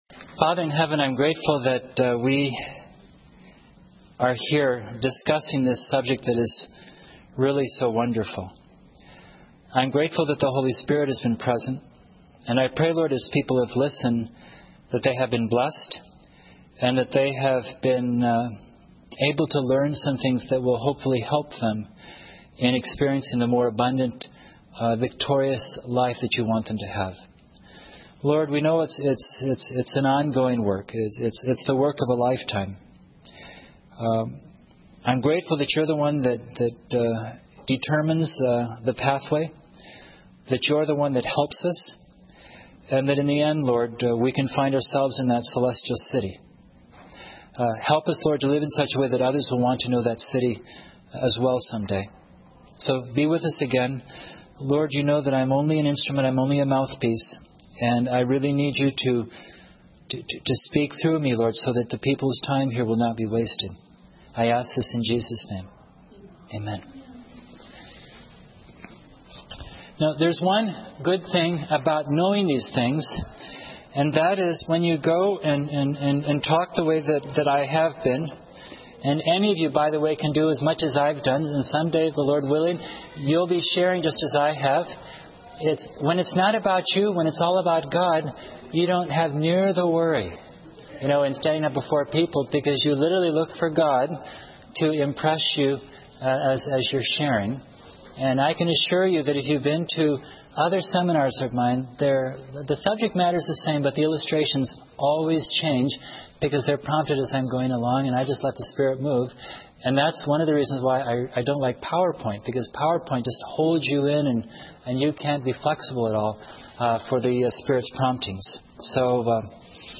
In this sermon, the speaker emphasizes the importance of recognizing and letting go of cherished sins and idols in our lives. God, who sees our hidden sins, works to separate these sins from us through a process of purification.